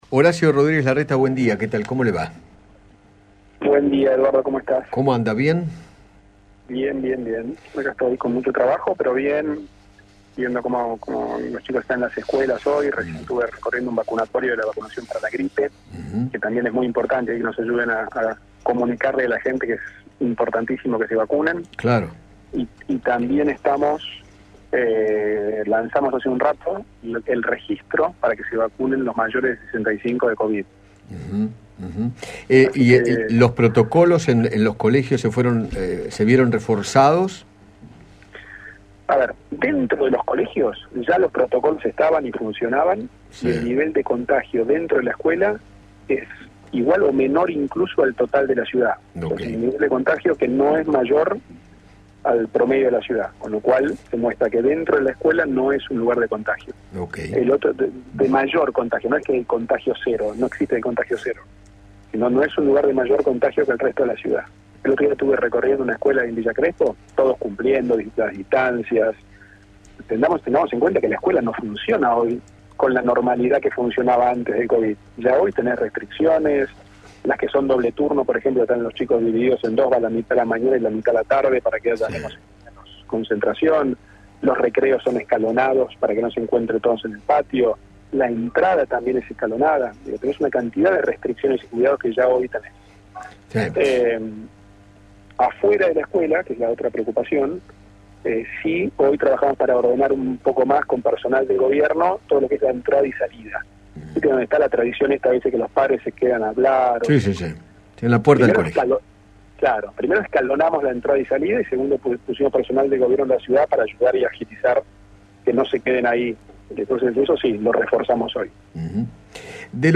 Horacio Rodriguez Larreta, jefe de Gobierno de la Ciudad de Buenos Aires, dialogó con Eduardo Feinmann sobre la baja tasa de contagios en las escuelas y se refirió a la campaña de vacunación.